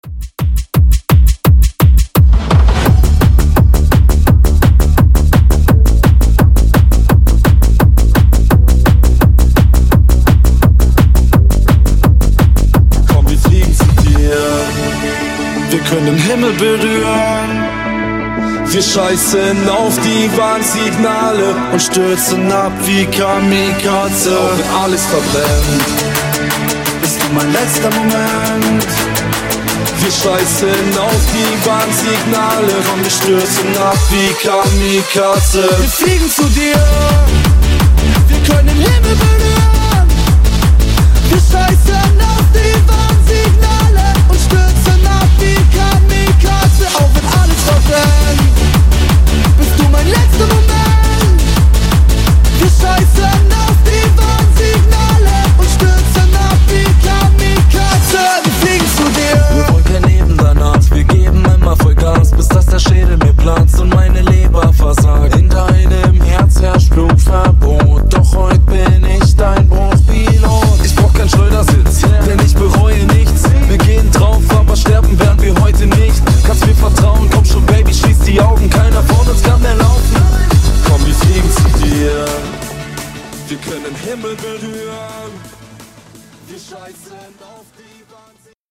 Genres: 2000's , GERMAN MUSIC , RE-DRUM
Clean BPM: 122 Time